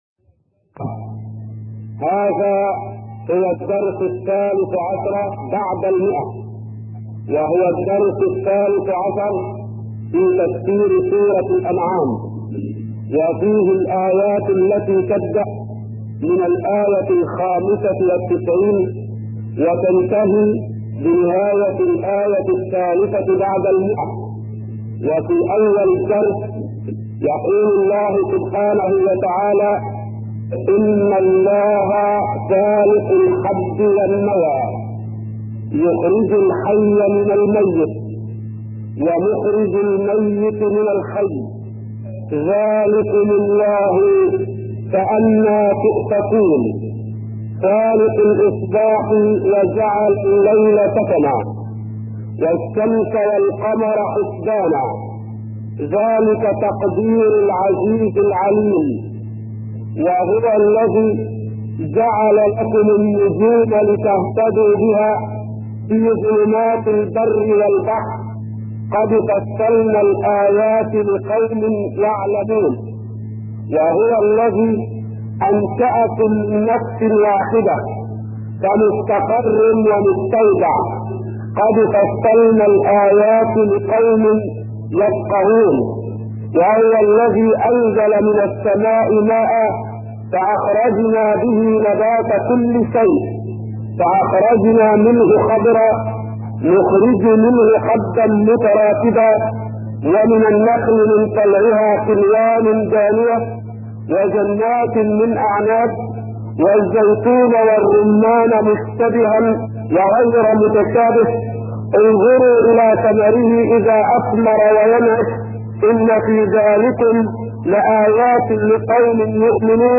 أرشيف صوتي لدروس وخطب ومحاضرات